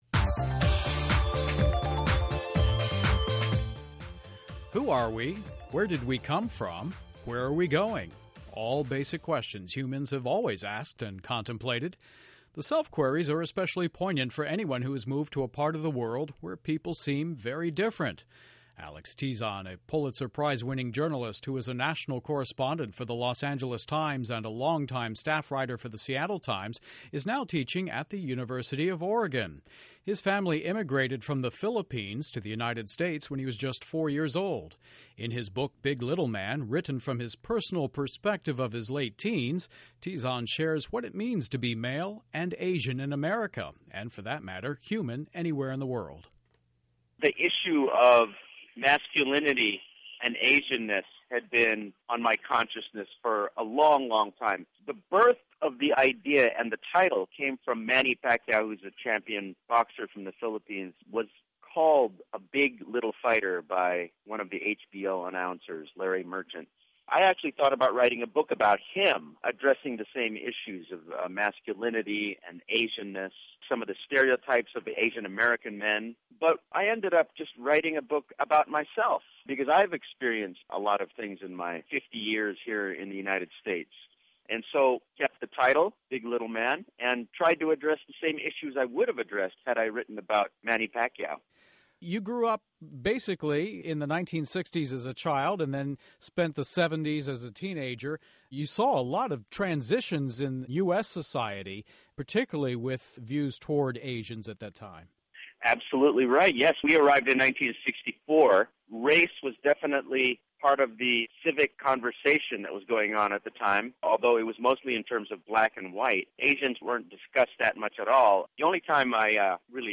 Q&A Authors